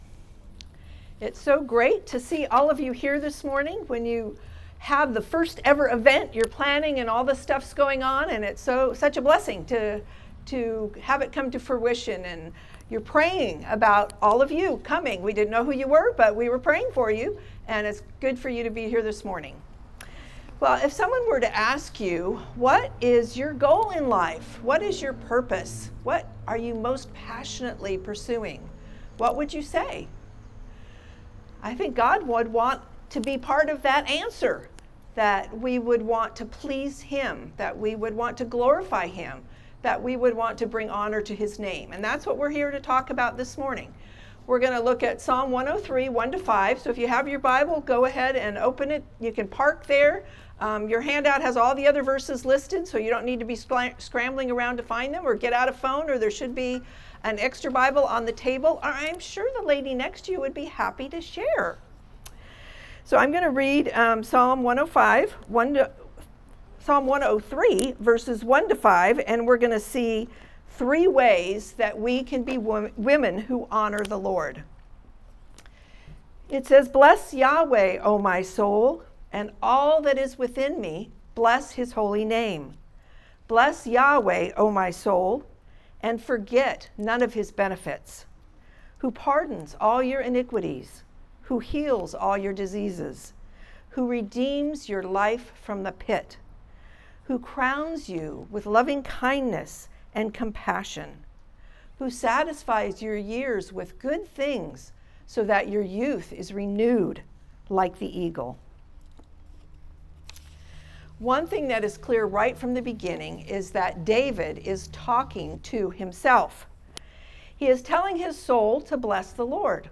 Women’s Breakfast - The God-Honoring Woman (Sermon) - Compass Bible Church Long Beach